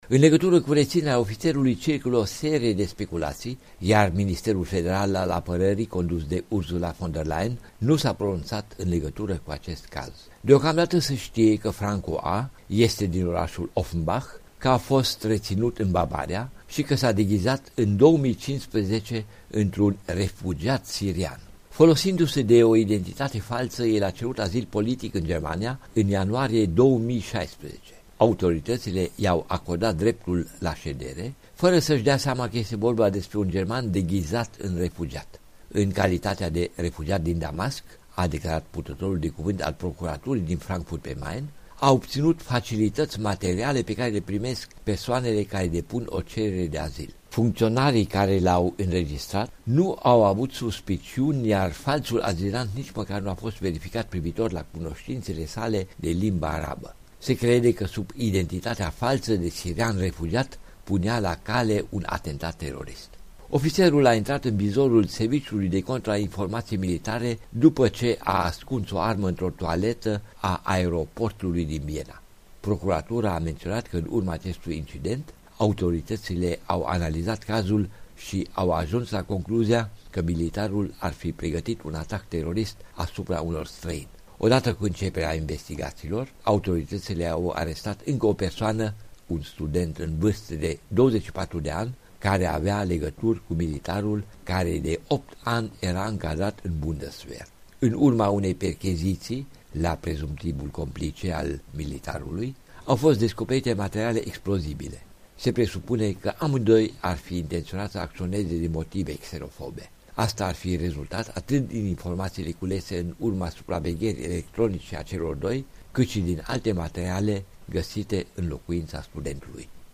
Corespondența zilei de la Berlin